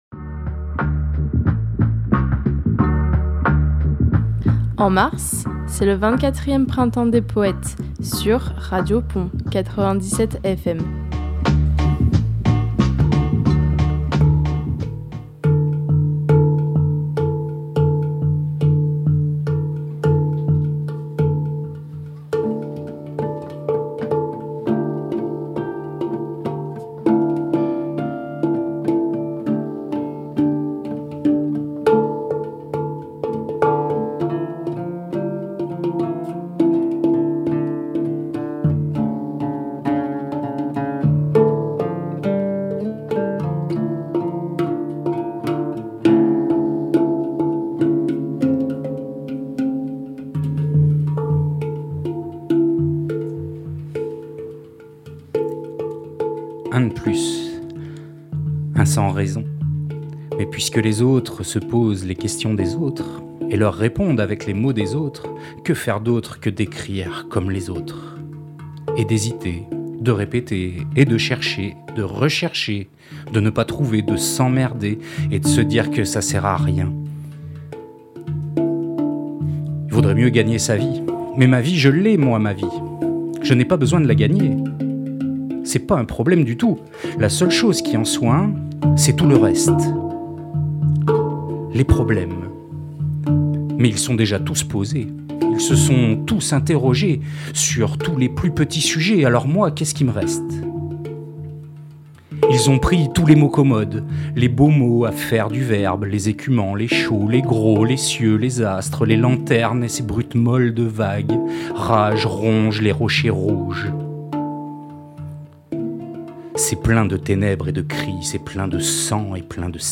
… 6 petites émissions éphémères enregistrées dans les conditions du direct